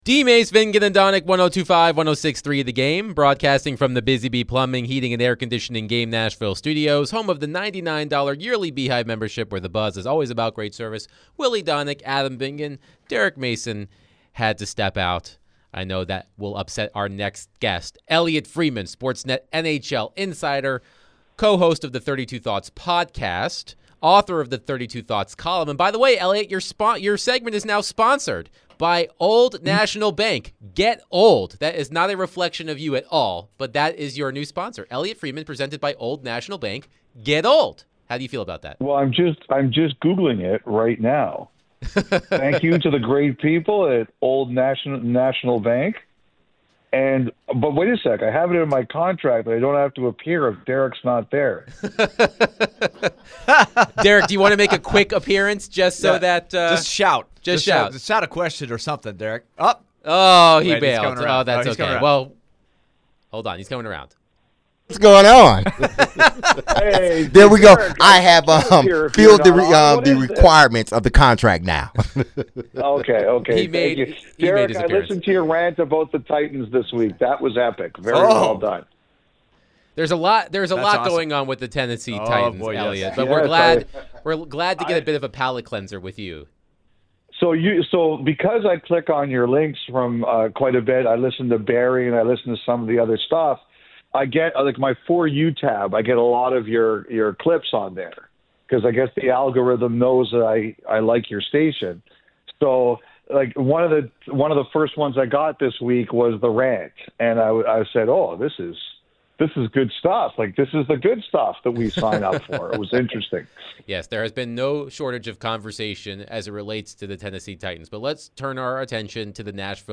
Sports Net NHL Insider Elliotte Friedman joins DVD to discuss the NHL, Nashville Predators and more